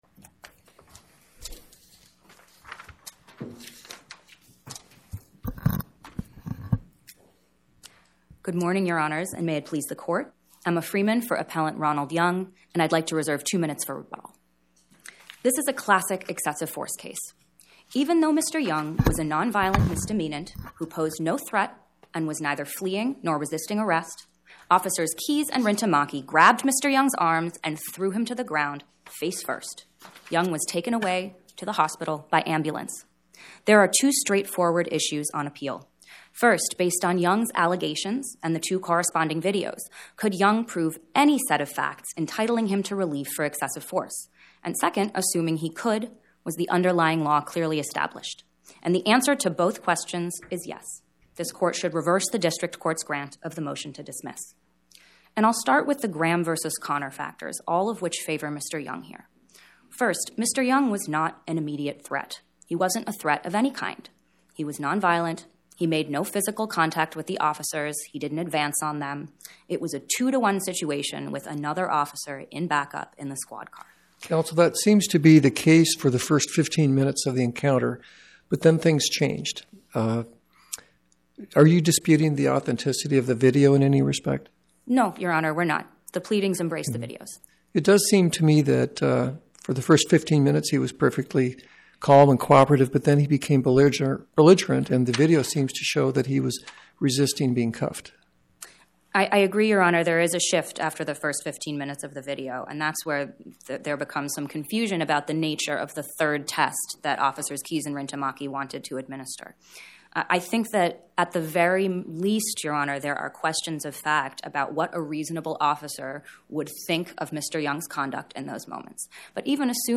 Oral argument argued before the Eighth Circuit U.S. Court of Appeals on or about 10/22/2025